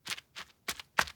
Sprint.wav